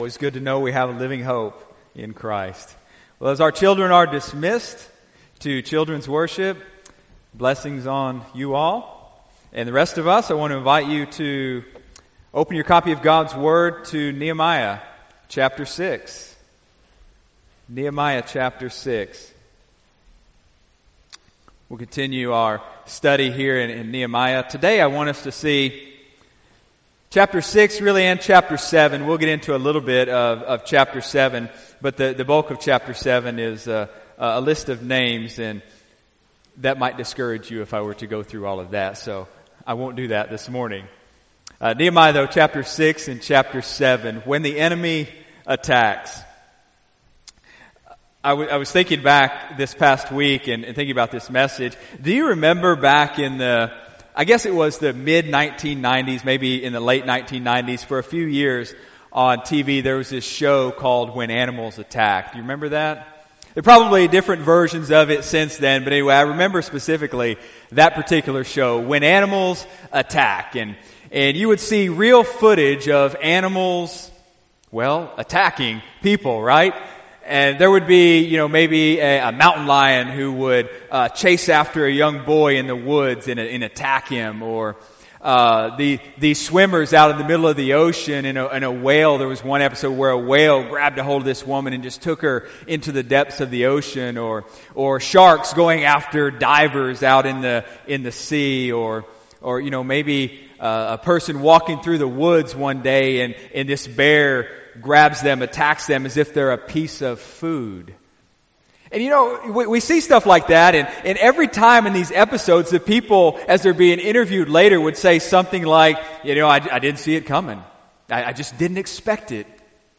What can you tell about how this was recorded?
Sunday, December 9, 2018 (Sunday Morning Service)